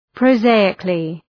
Προφορά
{prəʋ’zeııklı}